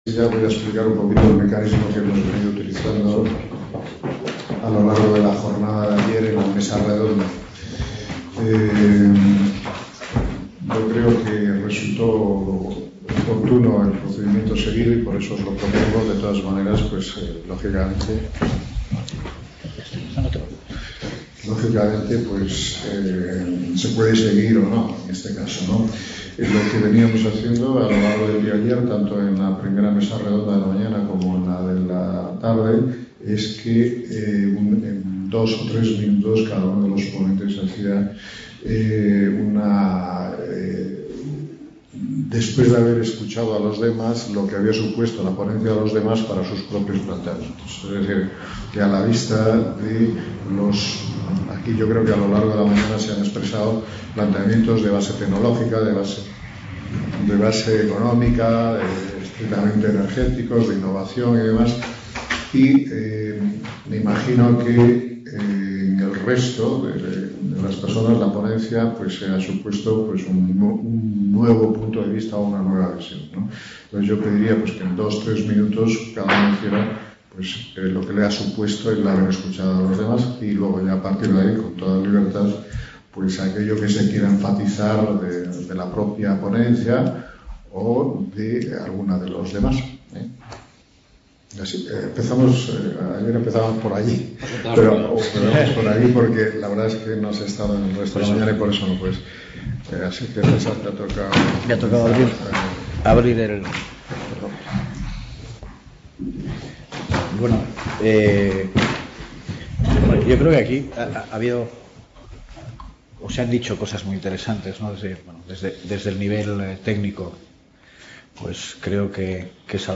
Mesa redonda Mañana Viernes - Recursos energéticos de…